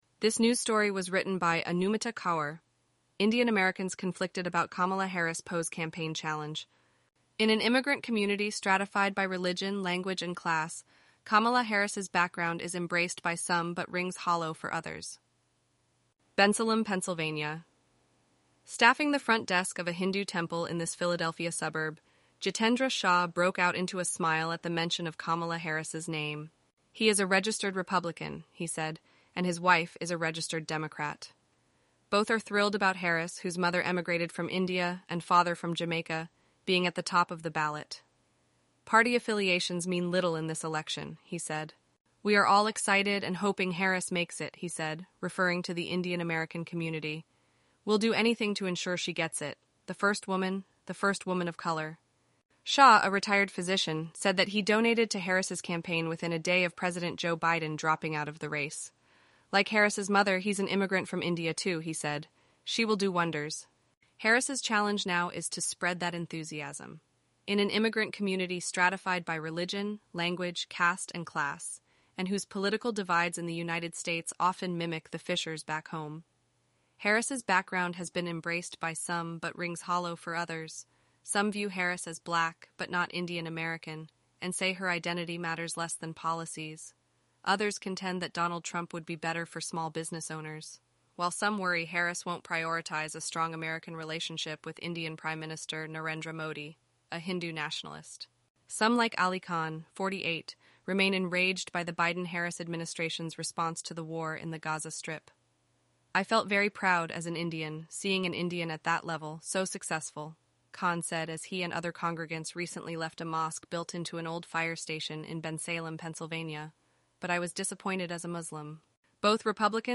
eleven-labs_en-US_Rachel_standard_audio.mp3